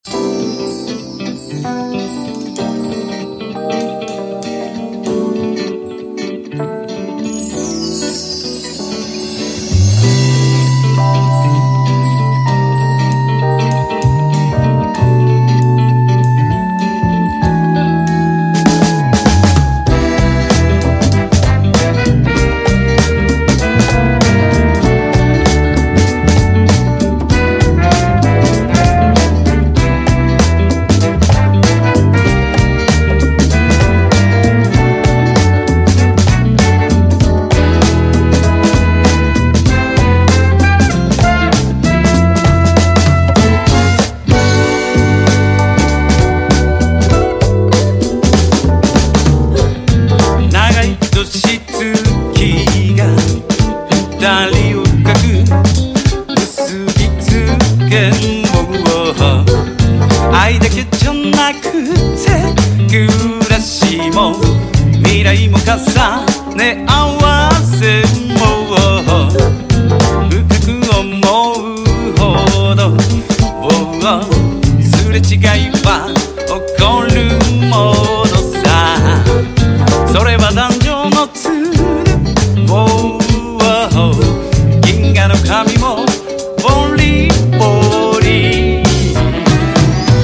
INDIE POP (JPN)